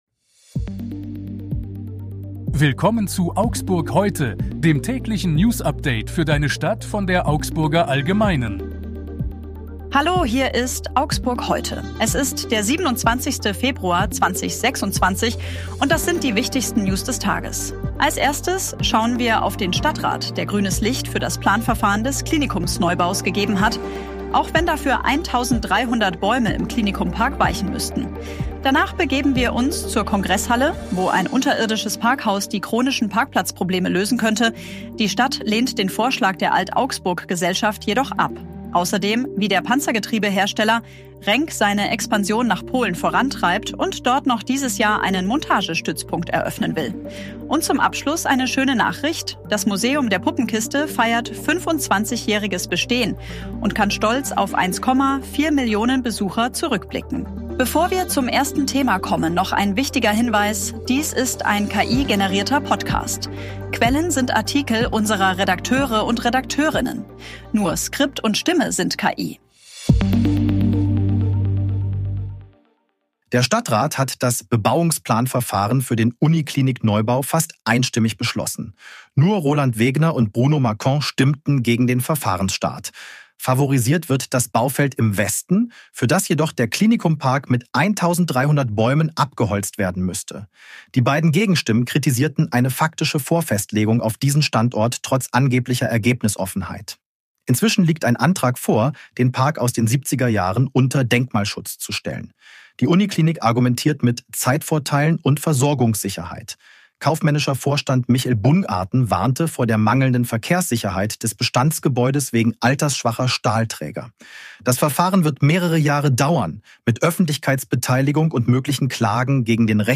KI.